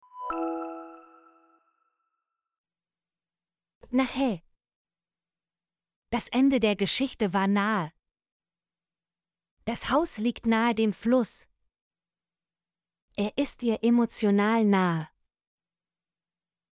nahe - Example Sentences & Pronunciation, German Frequency List